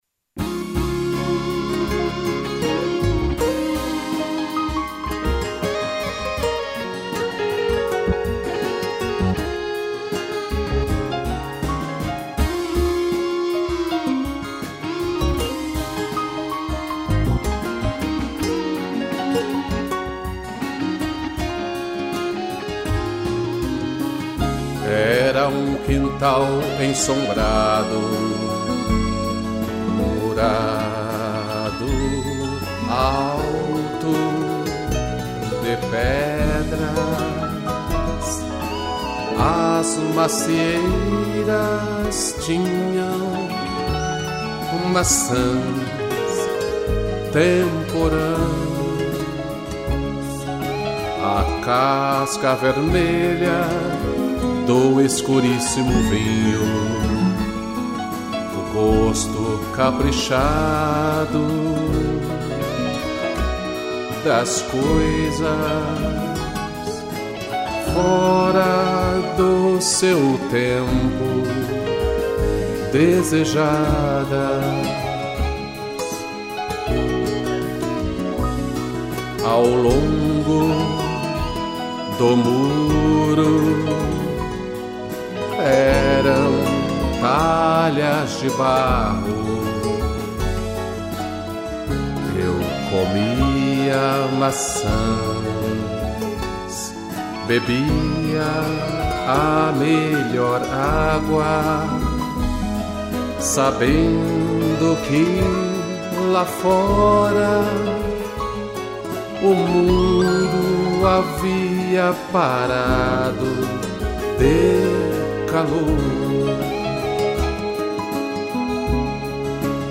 voz e violão
piano